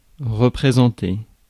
Ääntäminen
IPA: [ʁǝ.pʁe.zɑ̃.te]